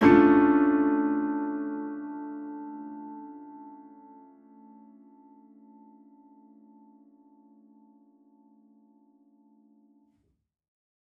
Index of /musicradar/gangster-sting-samples/Chord Hits/Piano
GS_PiChrd-Asus4min6.wav